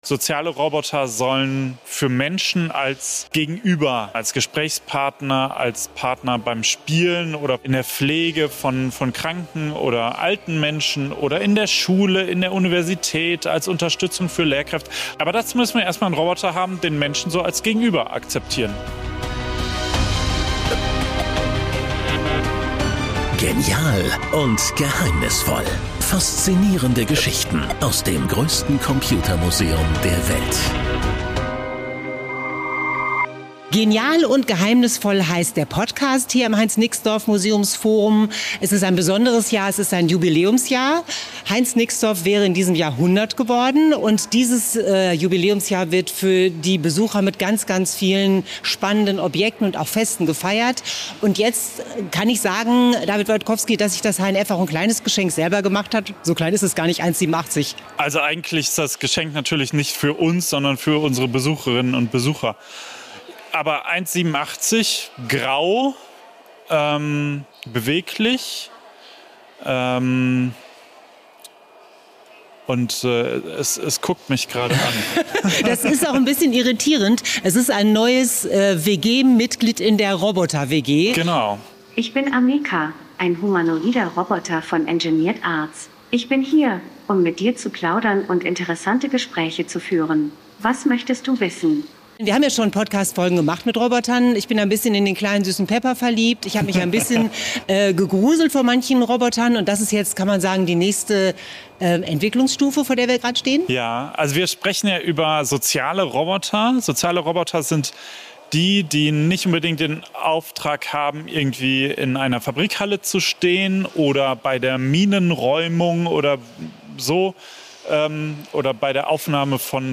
Dank ChatGPT verfügt der Roboter über einen großen Wortschatz sowie eine korrekte Grammatik und kann in fast allen gängigen Sprachen reden.